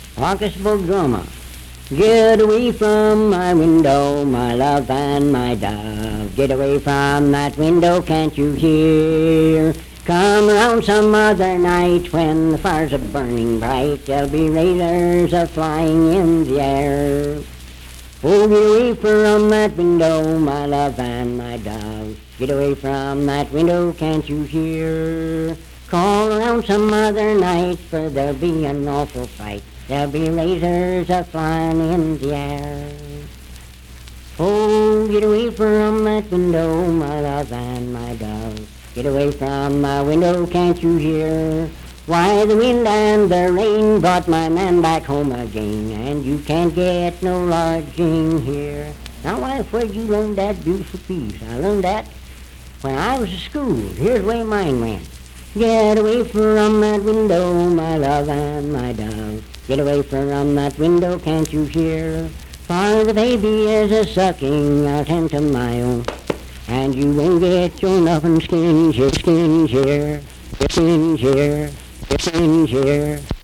Unaccompanied vocal music
Verse-refrain 4(4w/R). Performed in Sandyville, Jackson County, WV.
Bawdy Songs
Voice (sung)